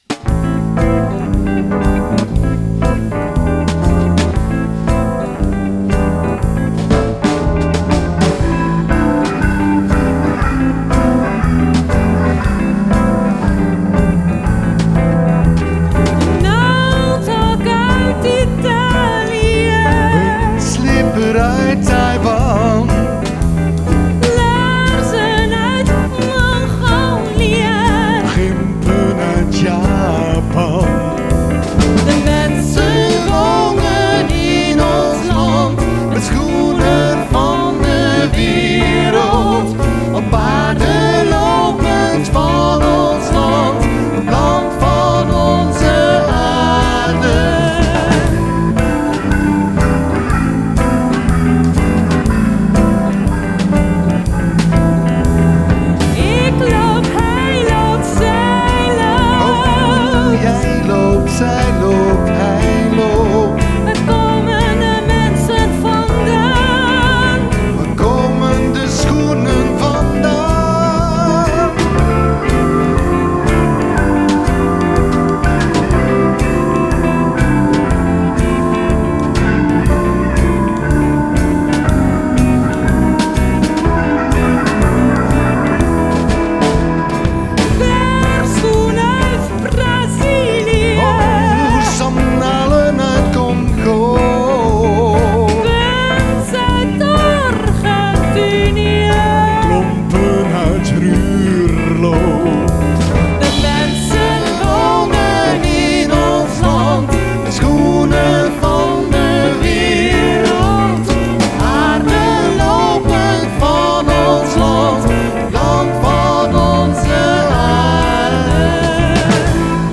Muziek